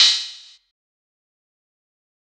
フリー効果音：剣
フリー効果音｜ジャンル：かんきょう、バトルっぽい効果音！かっこいい剣の金属音です！
sword.mp3